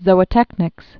(zōə-tĕknĭks)